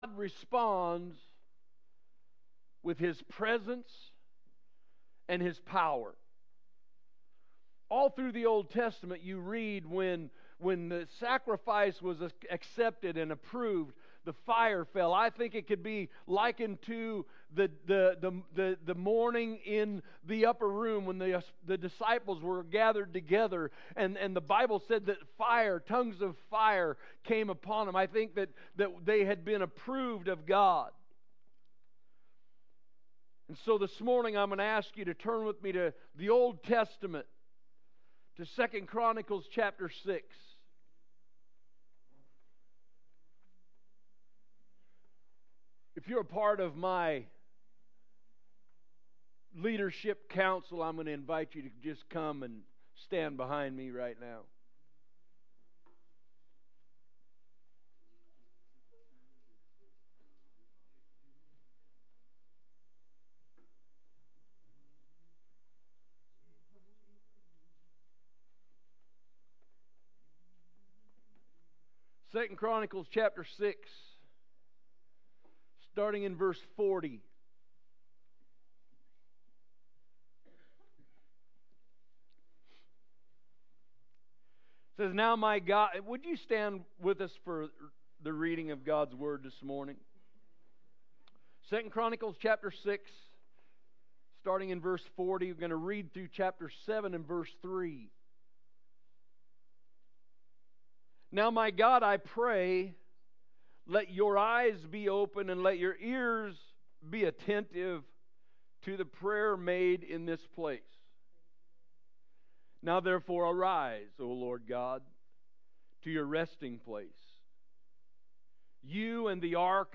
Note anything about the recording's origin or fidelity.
When the Fire Fell – Sunday Morning Service March 15, 2026